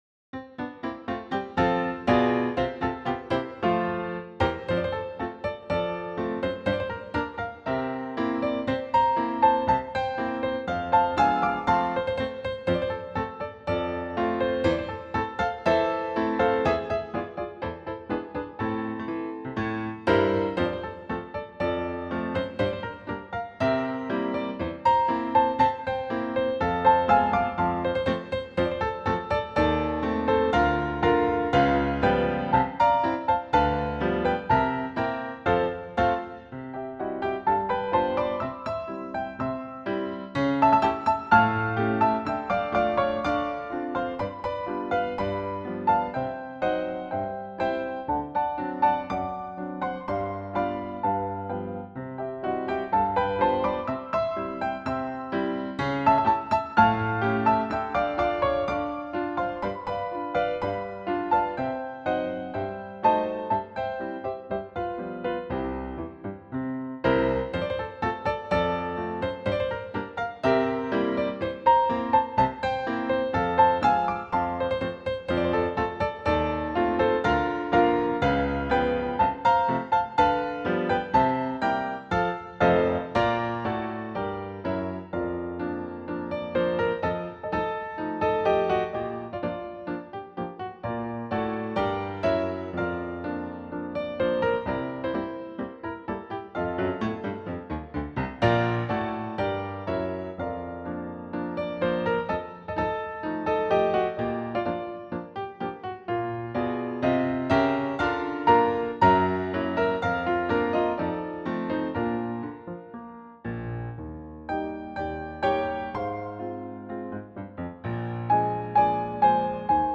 He was also commissioned by Edmund H. Pendleton, president of the Seventh Industrial Exposition, to compose a march for a military band for the opening of the Exposition in the completed Music Hall.